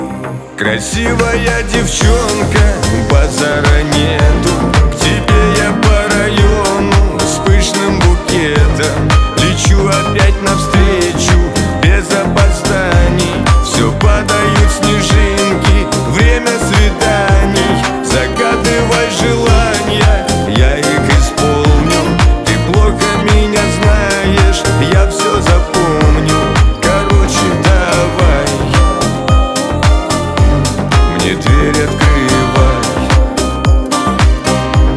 Жанр: Поп / Русские